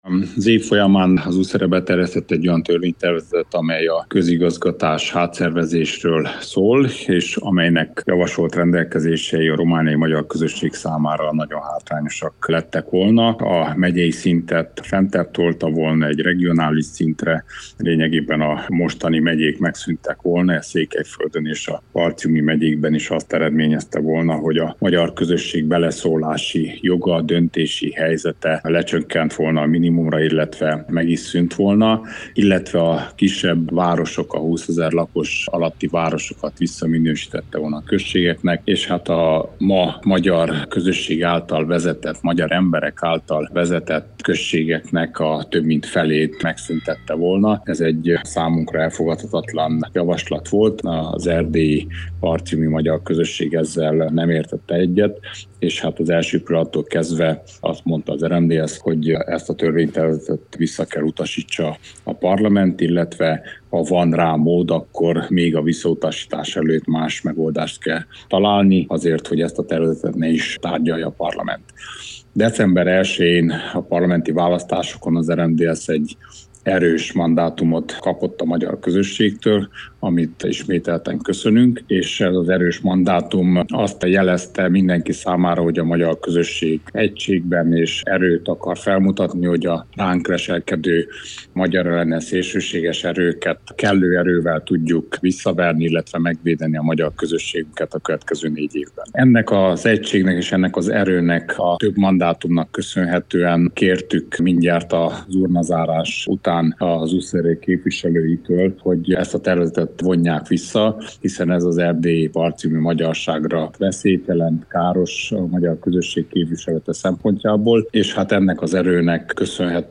Az RMDSZ szenátusi frakcióvezetőjét az USR közigazgatási átszervezési törvénytervezetének visszavonásáról kérdeztük.